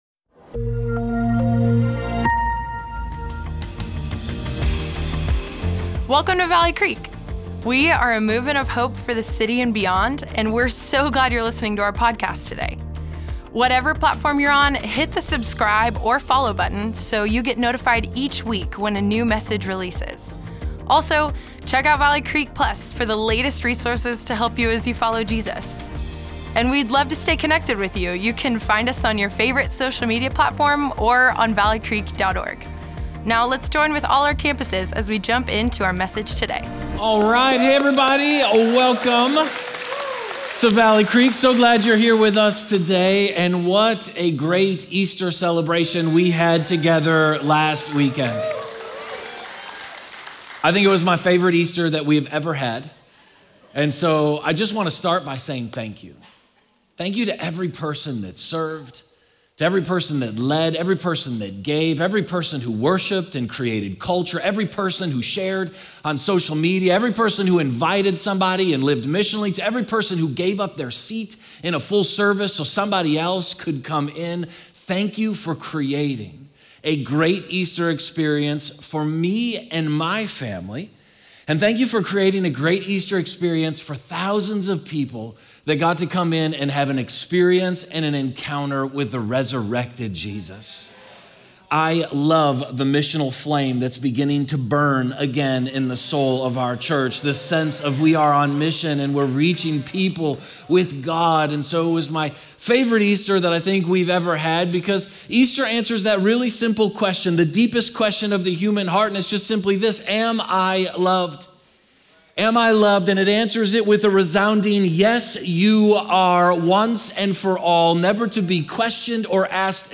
Weekend Messages